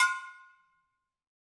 BrakeDrum1_Hammer_v2_Sum.wav